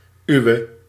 Ääntäminen
Ääntäminen Tuntematon aksentti: IPA: /ˈywə/ Haettu sana löytyi näillä lähdekielillä: hollanti Käännöksiä ei löytynyt valitulle kohdekielelle.